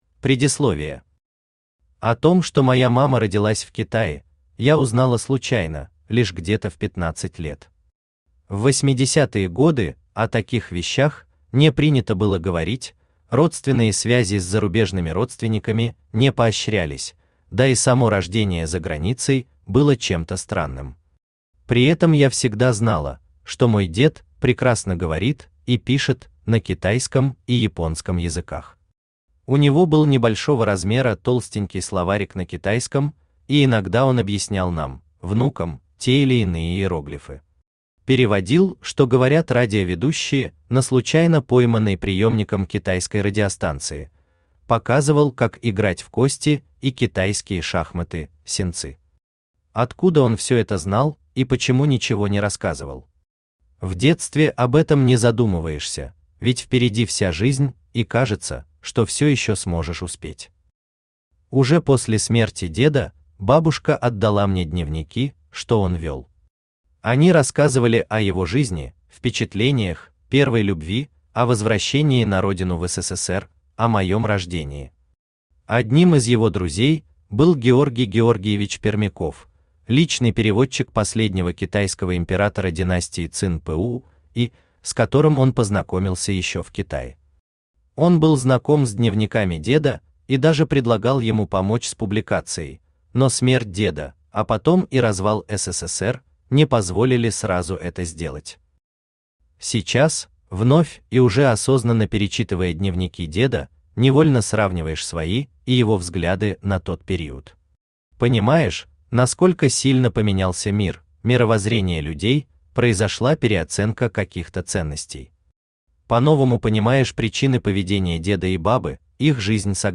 Аудиокнига У подножия Большого Хингана.
Aудиокнига У подножия Большого Хингана. Прапрадеды и деды Автор Всеволод Горячкин Читает аудиокнигу Авточтец ЛитРес.